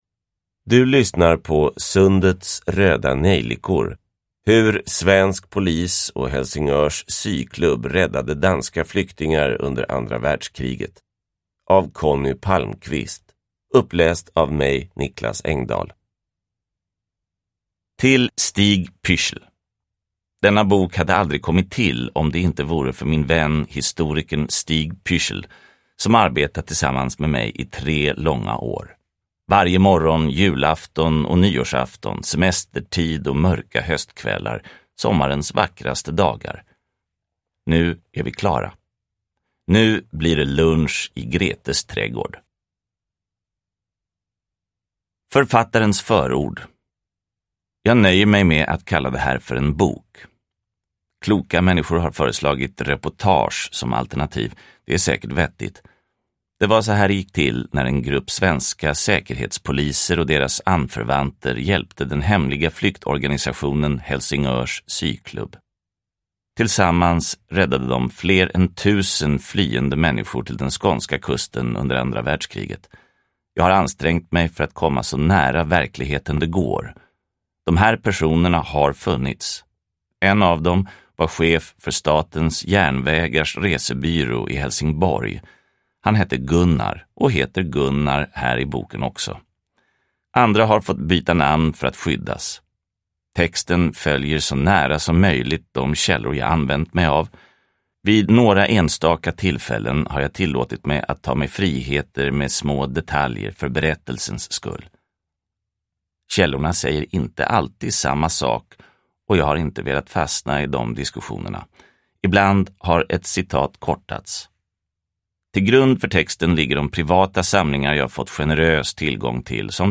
Sundets röda nejlikor : hur svensk polis och Helsingörs syklubb räddade danska flyktingar under andra världskriget – Ljudbok – Laddas ner